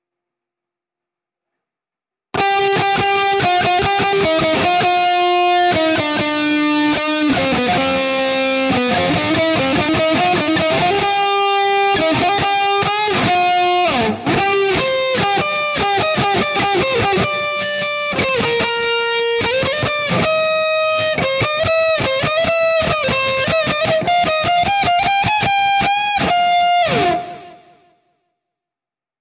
Вступление
Обработка через GuitarRIG 4